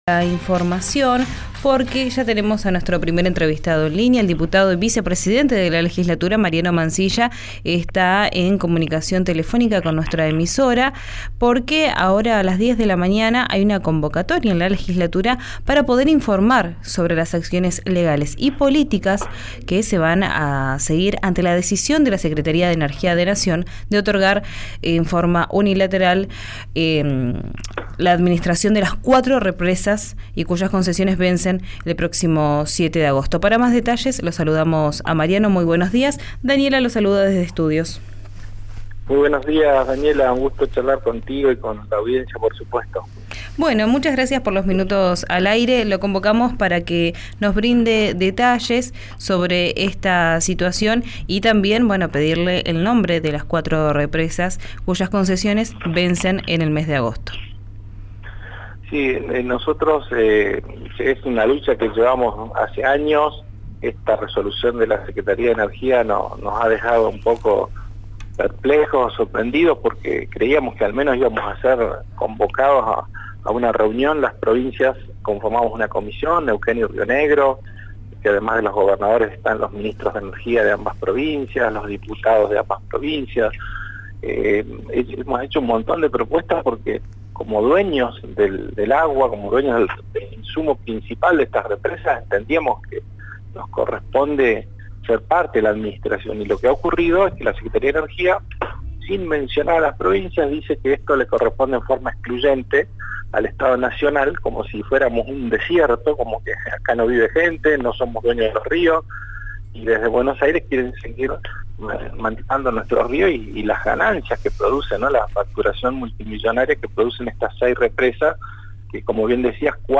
En diálogo con «Arranquemos» en RIO NEGRO RADIO, Mansilla destacó que la vía administrativa se hizo con copia al presidente de la Nación, Alberto Fernández.